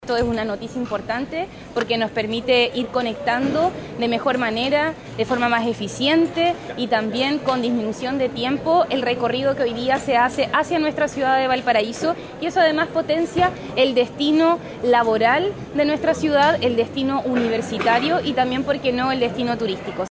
Por su parte, la alcaldesa de Valparaíso, Camila Nieto, destacó la llegada de los nuevos trenes y aseguró que estos potencian a la ciudad como núcleo laboral, universitario y turístico.